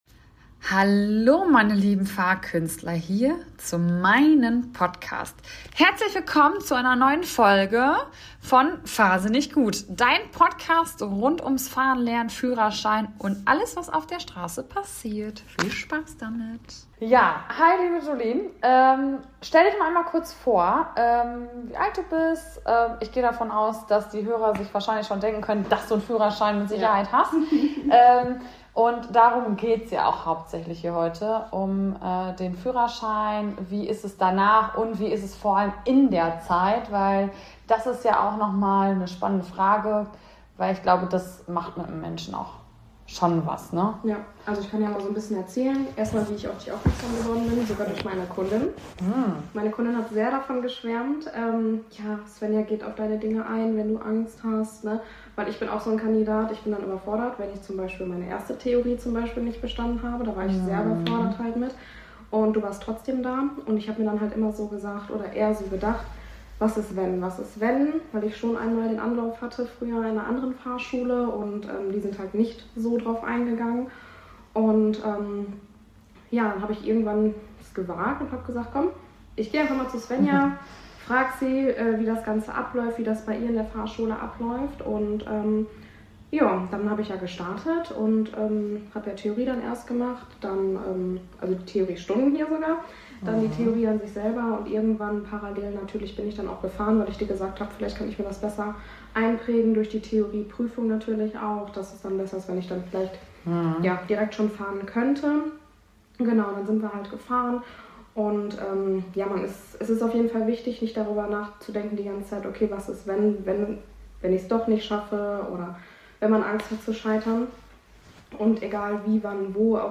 Ich habe eine ehemalige Fahrkünstlerin zu Gast und wir sprechen ganz offen über ihren Weg zum Führerschein – mit allen Höhen, Tiefen und Selbstzweifeln, die dazugehören. Wir reden darüber, wie es sich anfühlt, in der Theorie durchzufallen, warum man manchmal kurz davor ist aufzugeben und weshalb genau diese Momente am Ende die wichtigsten sind. Natürlich geht es auch um die praktische Prüfung, Nervosität im Auto und dieses unbeschreibliche Gefühl, wenn man es endlich geschafft hat.